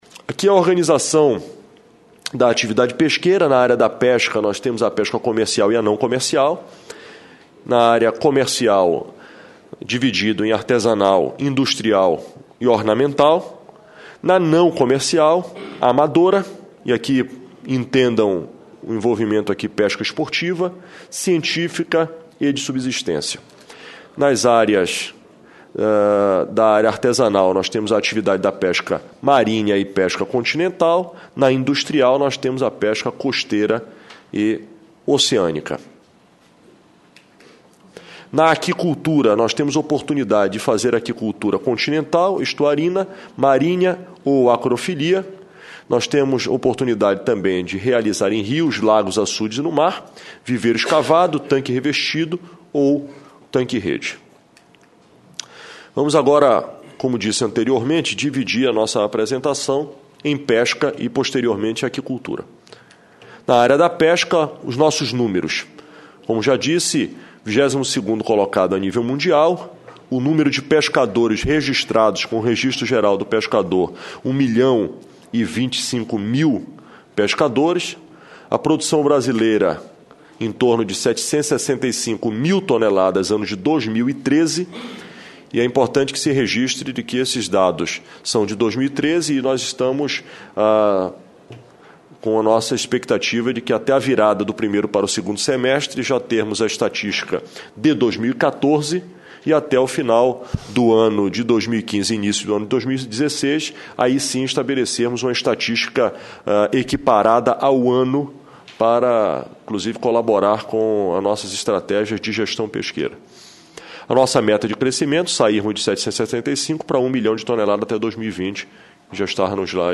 Confira a íntegra dos principais debates da Comissão de Agricultura e Reforma Agrária do Senado